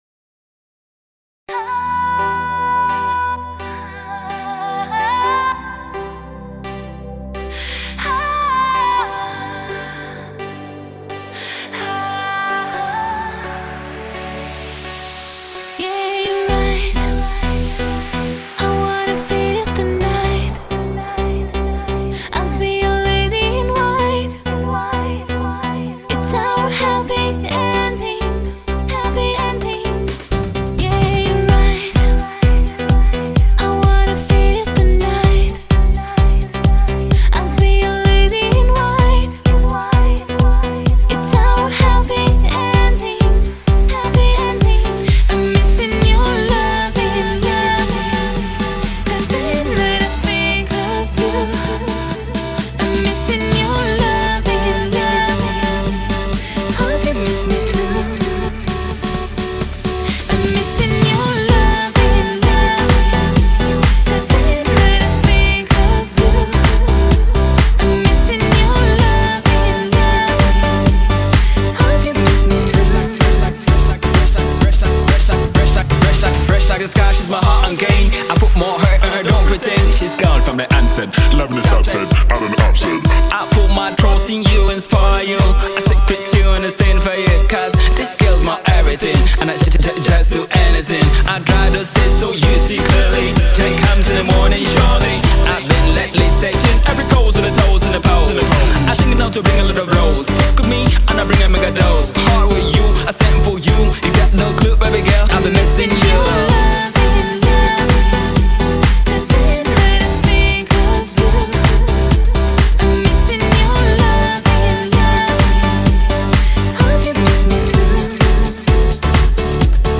музыка попса